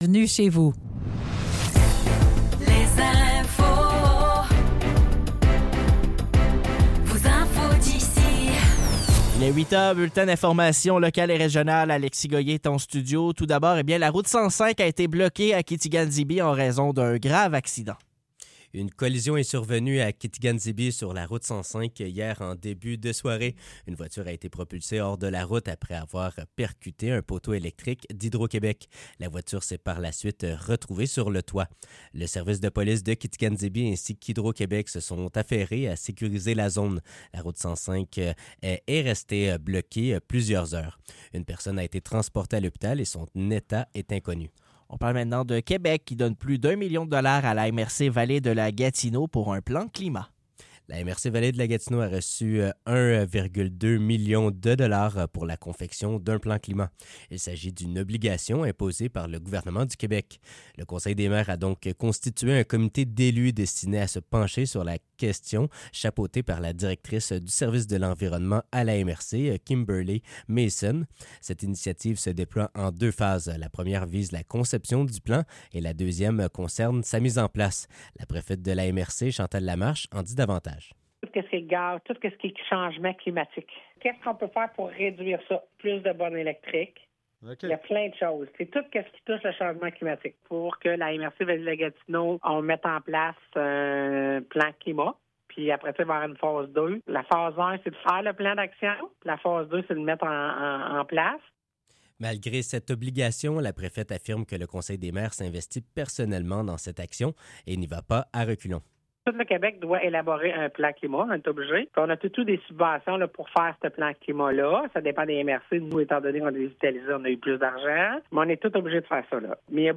Nouvelles locales - 22 novembre 2024 - 8 h